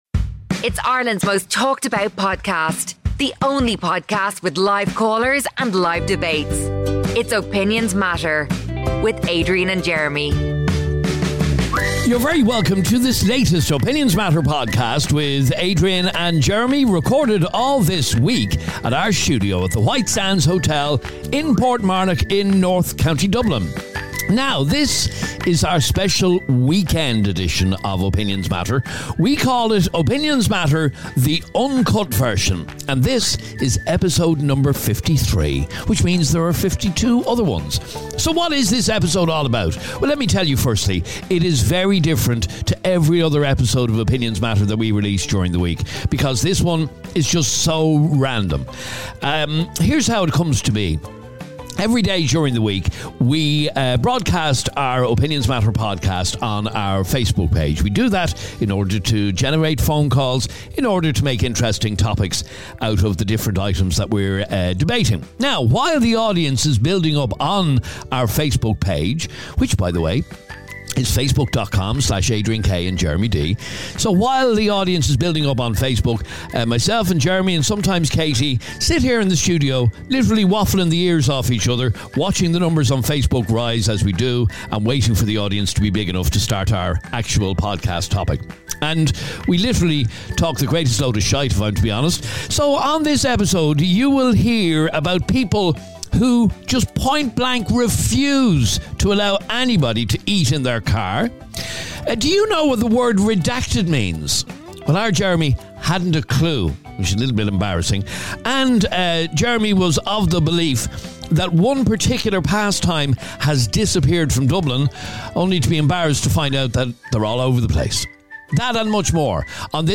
WARNING: contains bad language!!! Today there was war on the show over an incident that has happened in a creche in Santry, Dublin.
It's the angriest show we have ever done....